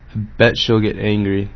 １６歳男性の音声